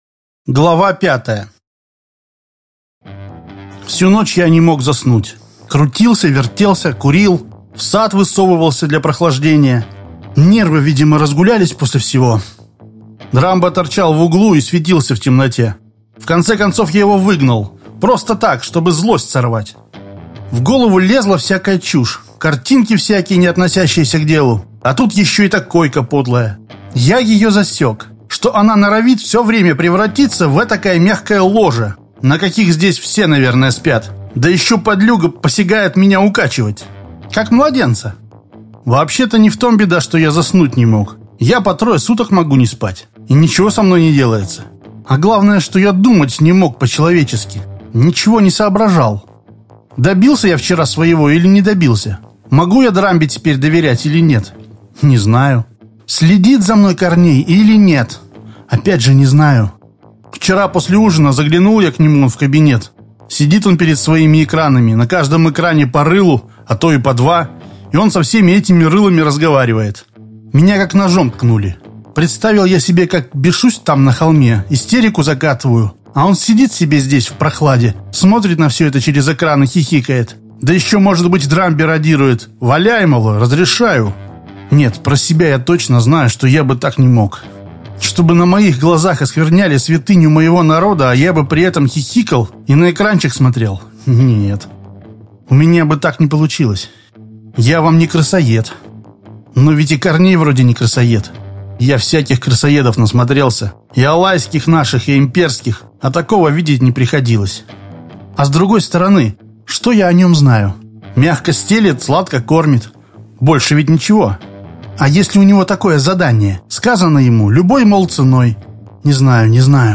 Аудиокнига Парень из преисподней. Часть 5.